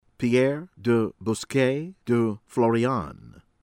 DE BRICHAMBAUT, MARC PERRIN MAHRK   peh-RAH(n)  duh  bree-sham-BOO